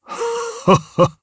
Dakaris-Vox-Laugh_jp.wav